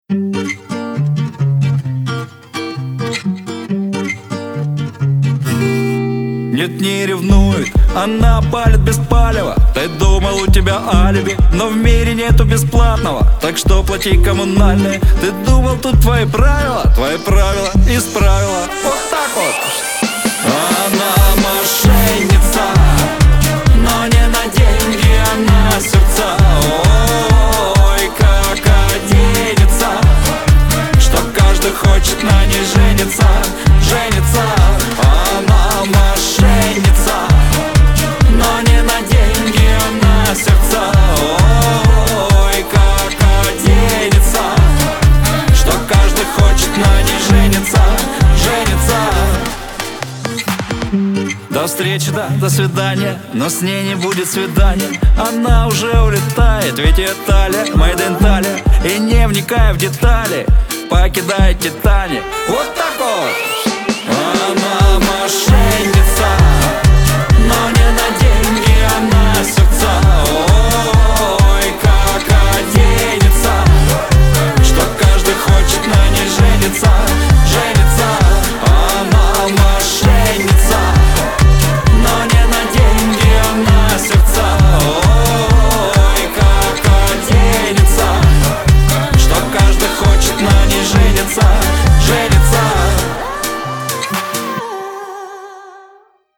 грусть , Шансон